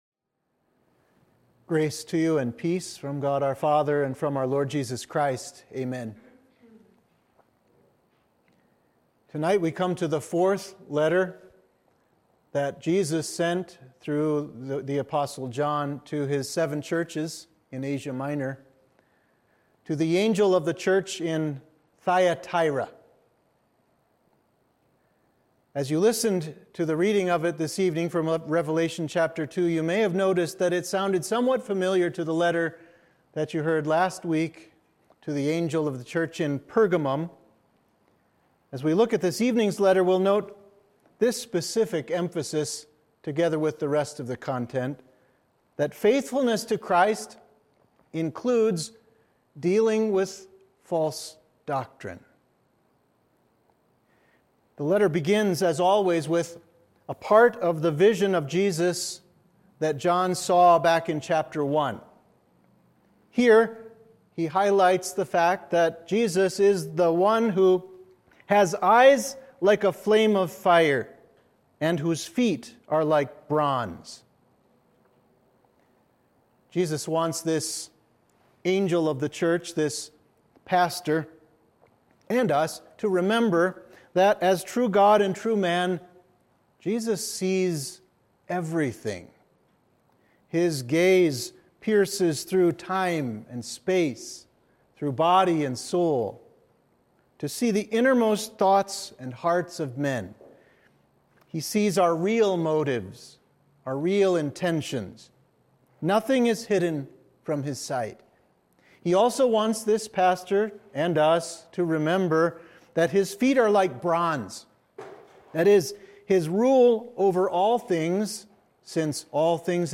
Sermon for Midweek of Reminiscere